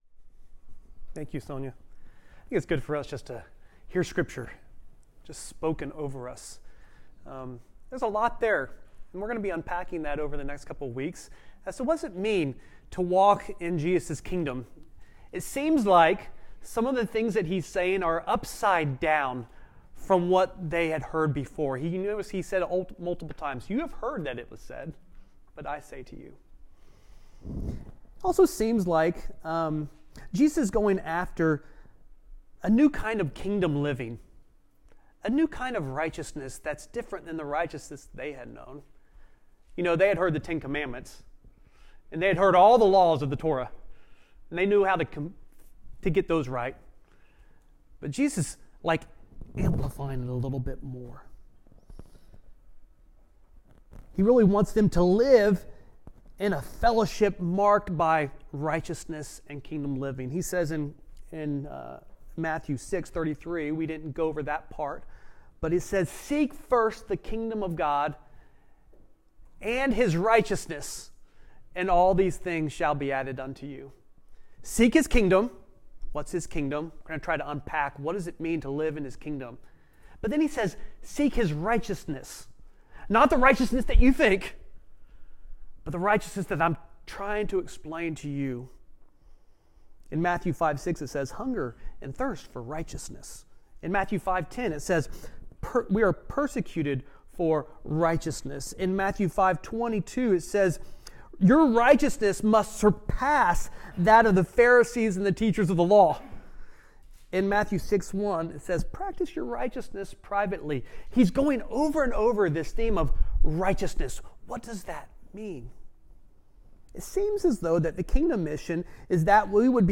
Sermons | The Gathering Church